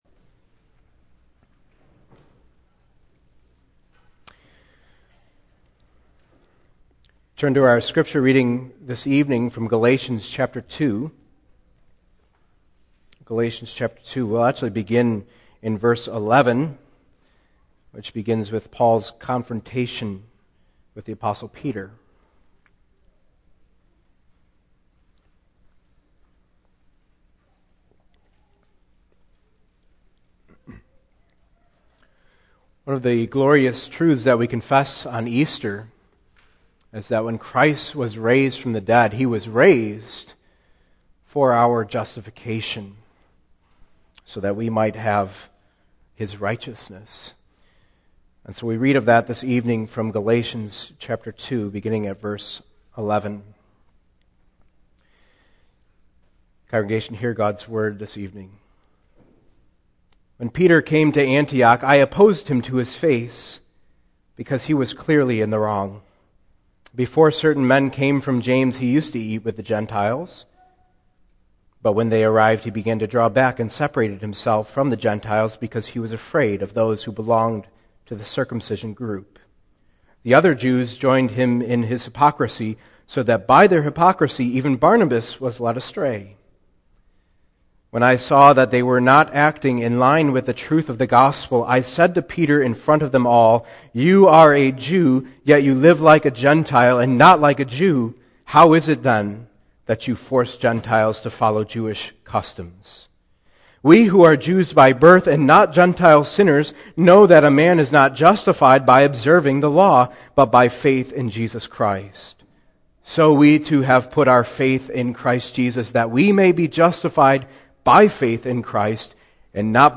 Single Sermons - Lynwood United Reformed Church - Page 5